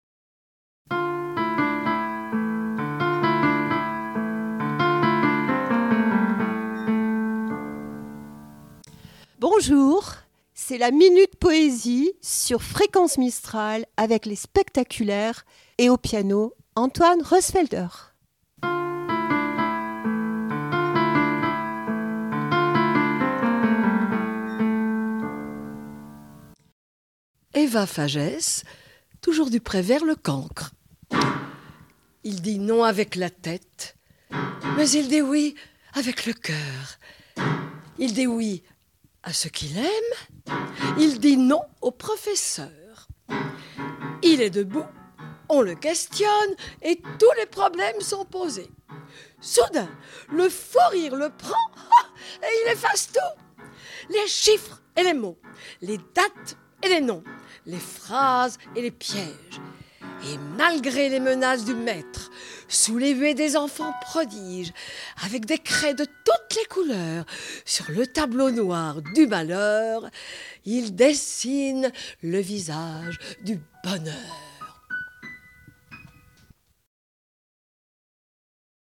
La poésie se murmure au rythme du piano sur Fréquence Mistral
Poesie- Le cancre.mp3 (896.19 Ko) Chronique poésie sur Fréquence Mistral avec : "les Spectaculaires", une troupe amateur qui fait s’envoler les mots au son du piano, une parenthèse sonore où les mots dansent , entre poésie et émotion